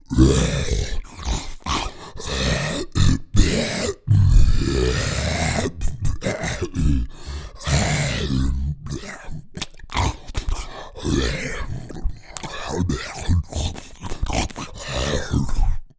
troll_eating_1.wav